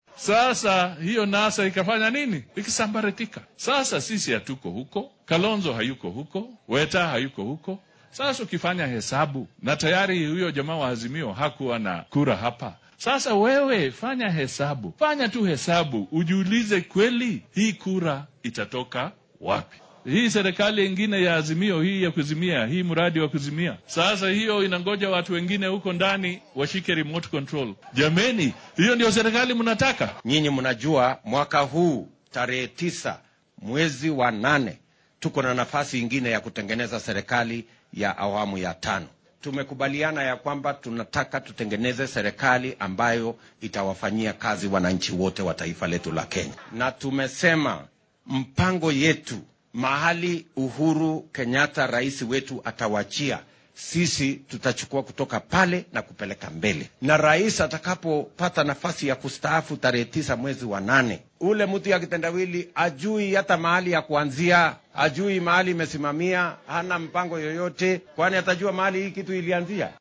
Hoggaamiyaasha siyaasadeed ee isbeheysiga Kenya Kwanza ee kala ah madaxweyne ku xigeenka wadanka William Ruto, madaxa ANC Musalia Mudavadi iyo dhigiisa Ford-Kenya Moses Wetangula oo maanta ololahooda siyaasadeed geeyay ismaamulka Murang’a ayaa dhaliil u jeediyay garabka Azimio ee uu hoggaamiyo madaxa xisbiga ODM Raila Odinga.